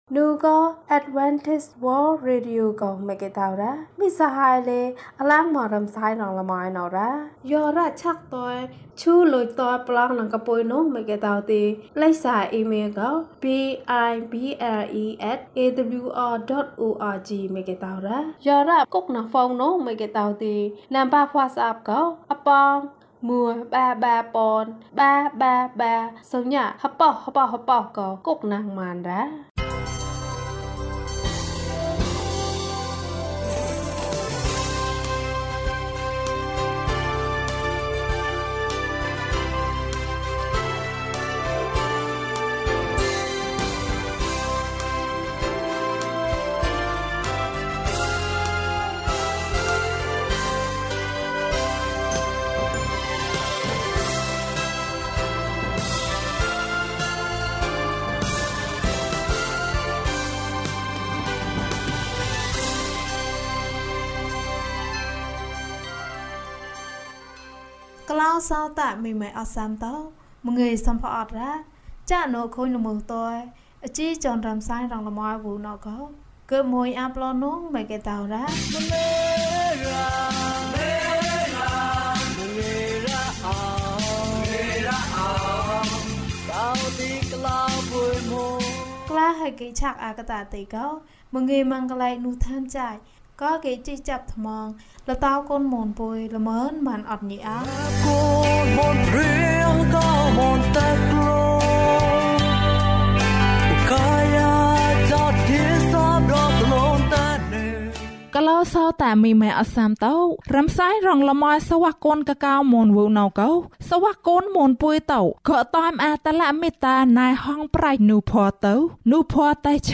ထွန်းလင်းသောကြယ်။ ကျန်းမာခြင်းအကြောင်းအရာ။ ဓမ္မသီချင်း။ တရားဒေသနာ။